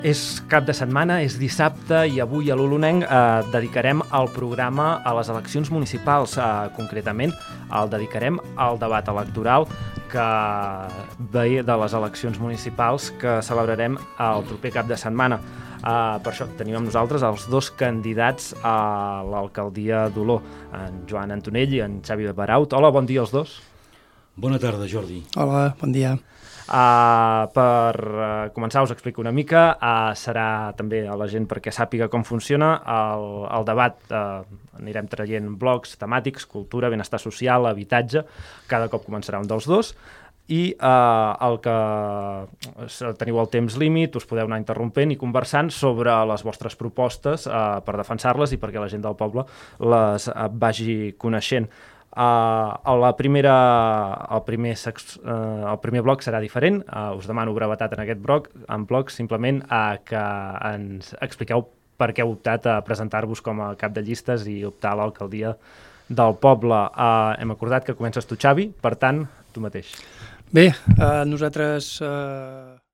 42139c95c15540ffa92592ba6671c093101fa4b5.mp3 Títol Ràdio 010 Emissora Ràdio 010 Titularitat Pública municipal Nom programa L'olonenc Descripció Presentació del debat de les eleccions municipals. Gènere radiofònic Informatiu Data emissió 2023-05-20 Banda FM Localitat Santa Maria d'Oló Comarca Moianès Durada enregistrament 01:12 Idioma Català Notes Extret del web de Ràdio 010.